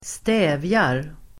Ladda ner uttalet
Uttal: [²st'ä:vjar]